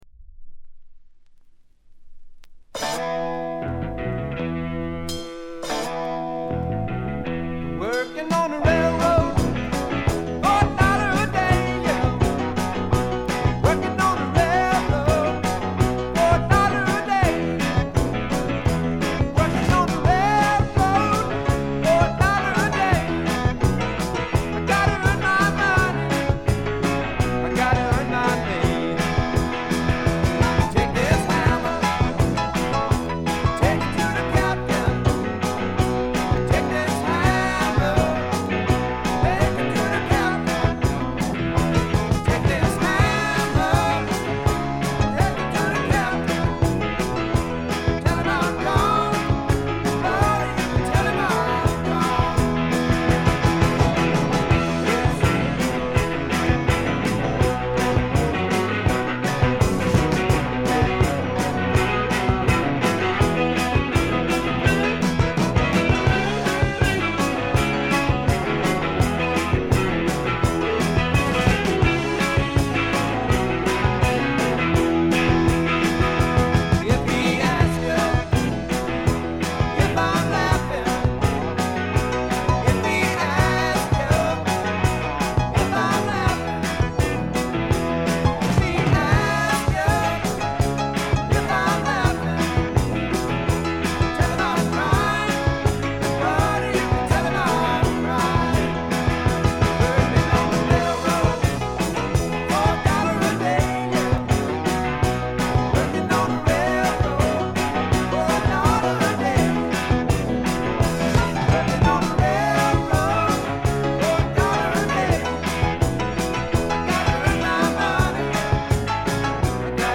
軽いチリプチが少々。
試聴曲は現品からの取り込み音源です。